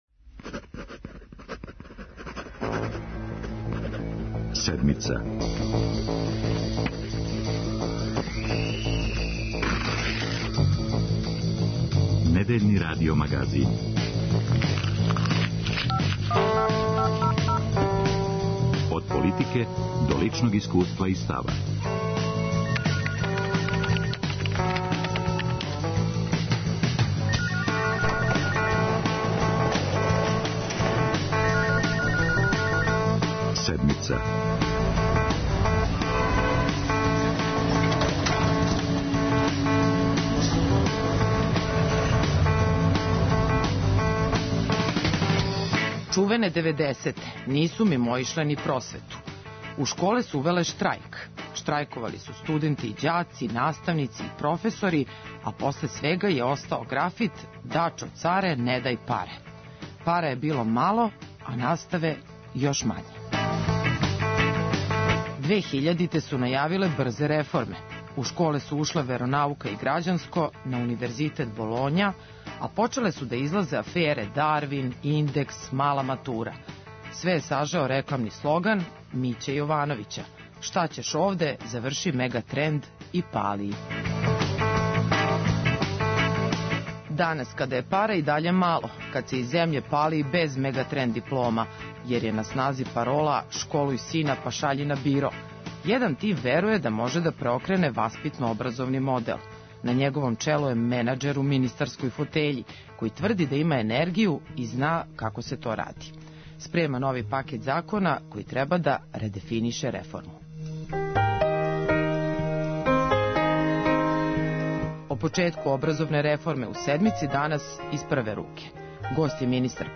Директор у министарској фотељи. Гост Седмице је Младен Шарчевић, у Немањиној 11 задужен за просвету, науку и технолошки развој